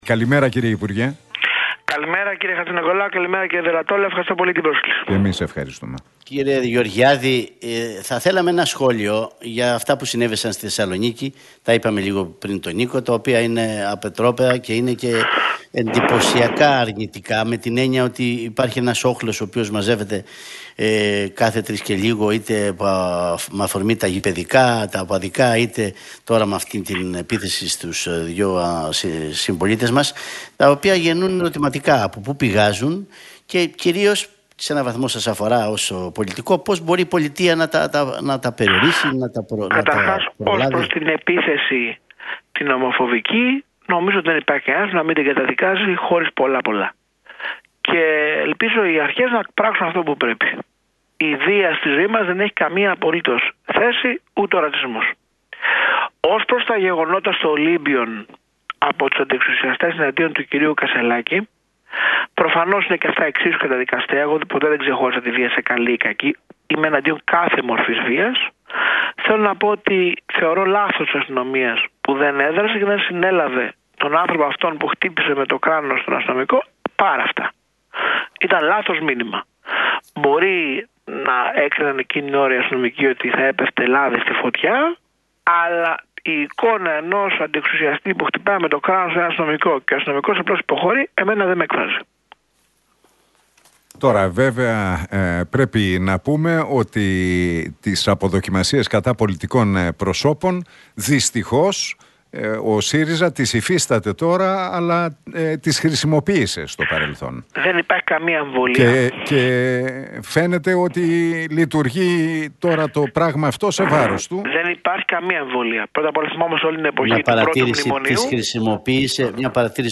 Γεωργιάδης στον Realfm 97,8: Συζητάμε σχεδόν 40 χρόνια για τα απογευματινά χειρουργεία